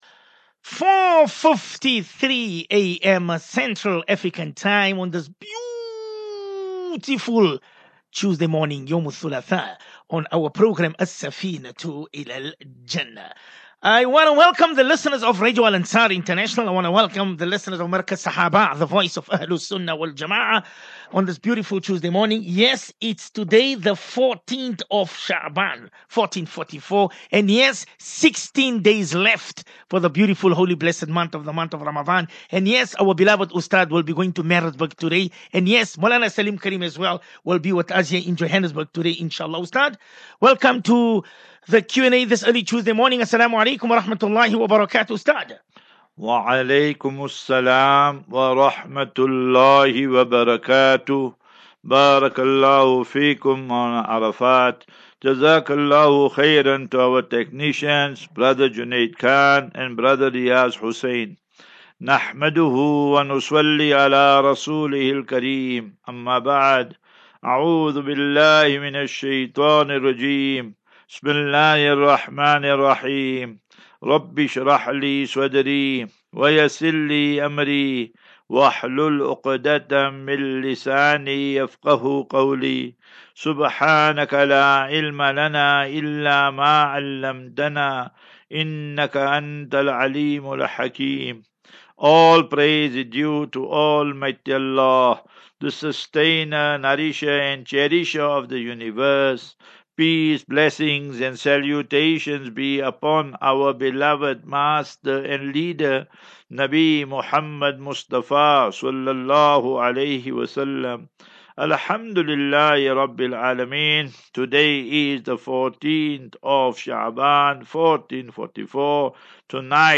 View Promo Continue Install As Safinatu Ilal Jannah Naseeha and Q and A 7 Mar 07 Mar 23 Assafinatu-illal -Jannah 35 MIN Download